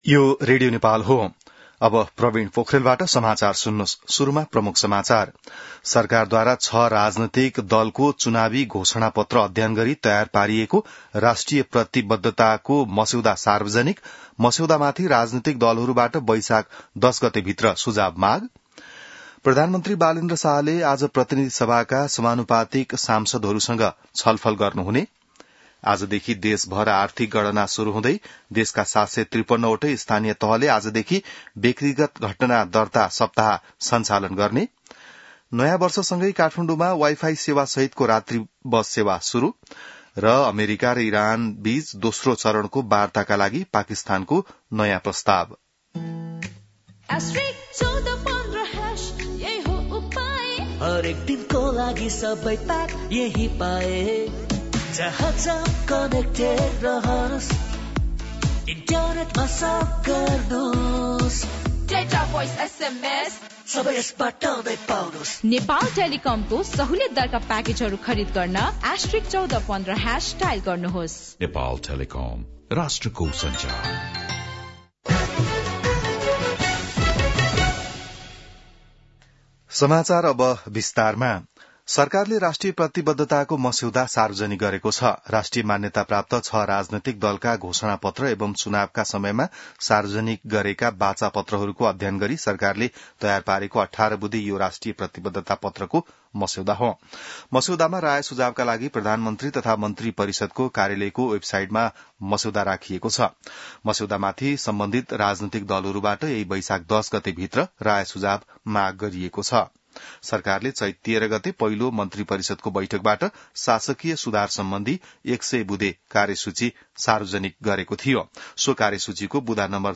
बिहान ७ बजेको नेपाली समाचार : २ वैशाख , २०८३